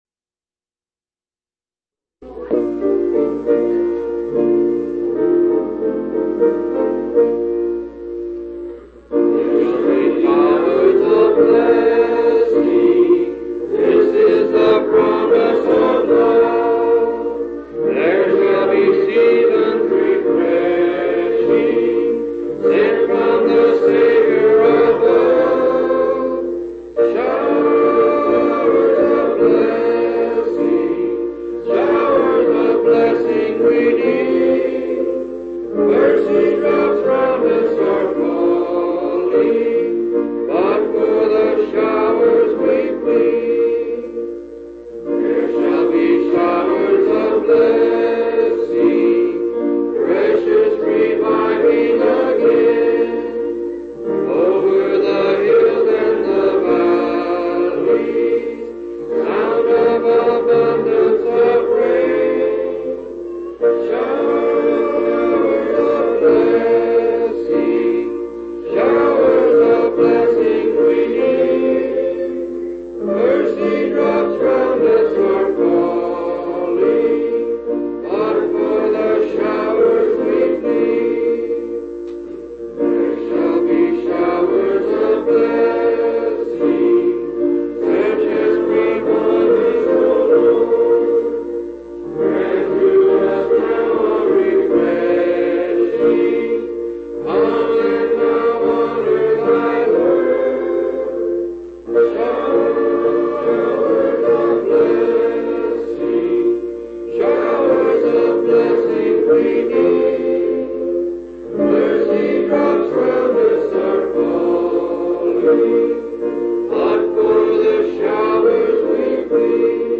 11/13/1983 Location: Phoenix Local Event